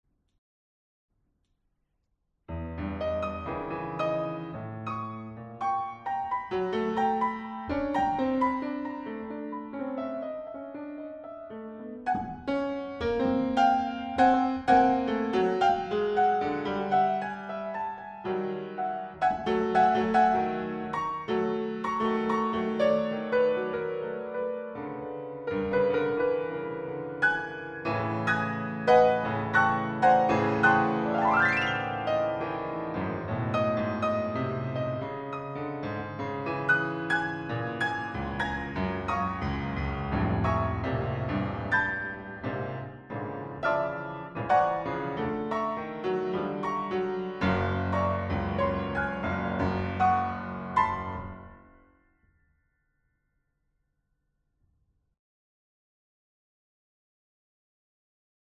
For one piano.
Recording DDD of Centro Studi Assenza; jan. 2000